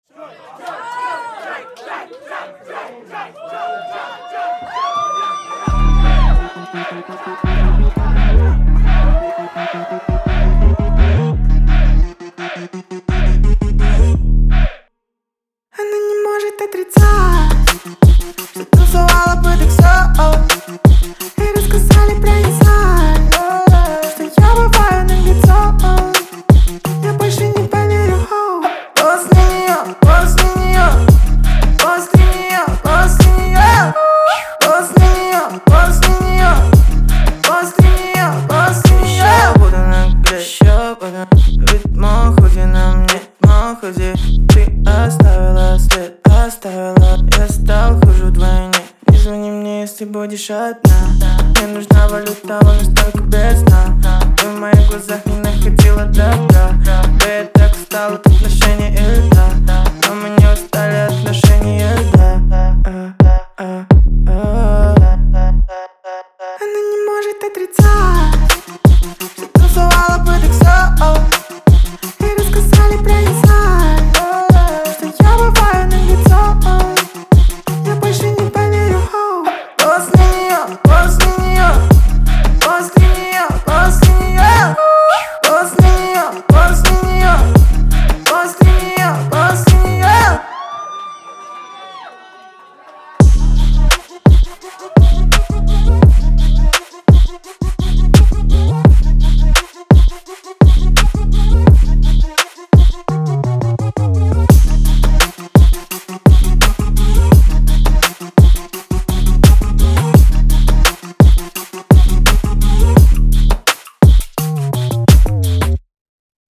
это эмоциональный трек в жанре альтернативного рока